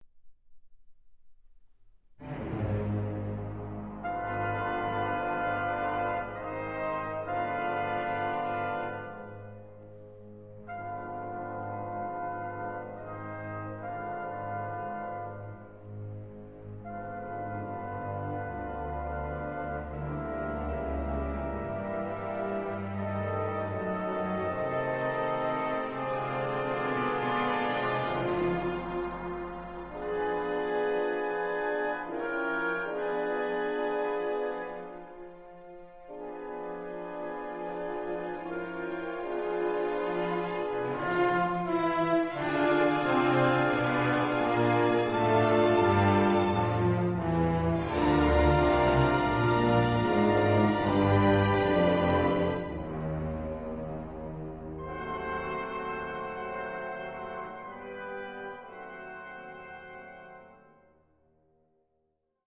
5) Fantasy for orchestra after Dante 24:30